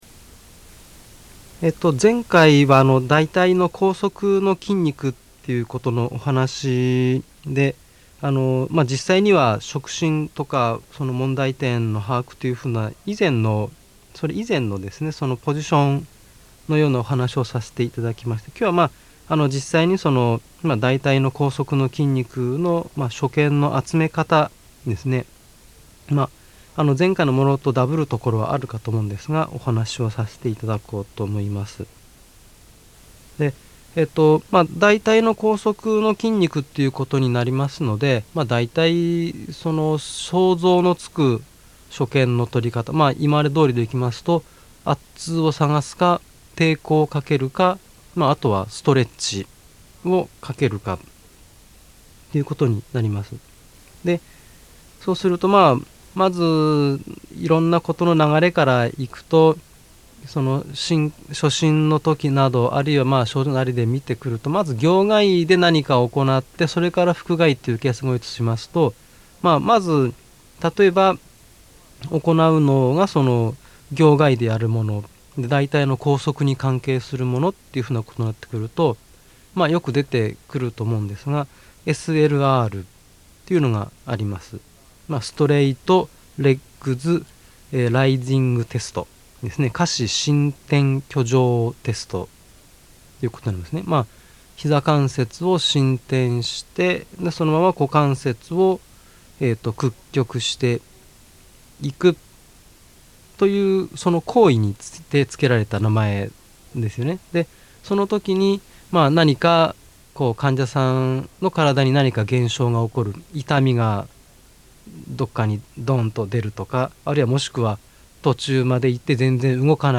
今回は、大腿後側筋の診方について（その②）、 お話ししています。 社会福祉法人 日本点字図書館様作成の 2004年1月のテープ雑誌 新医学より一部抜粋したものです。